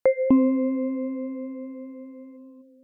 notify.wav